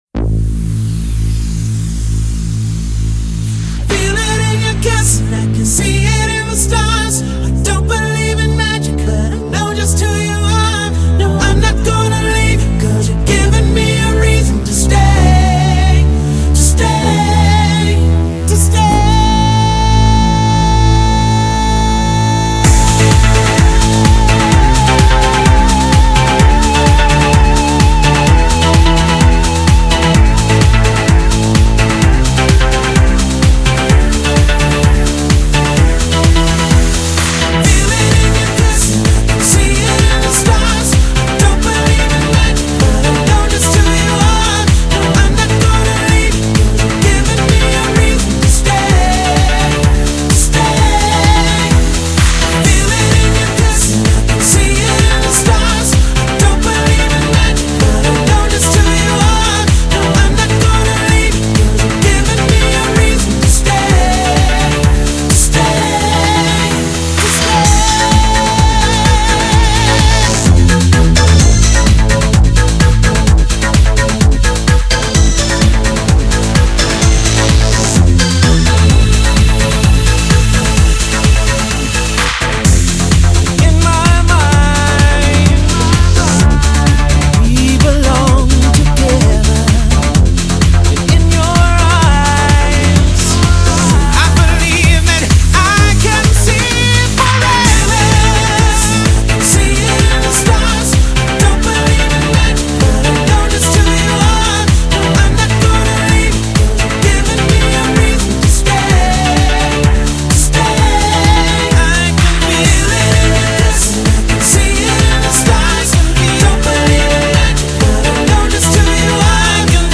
Хаус House Хаус музыка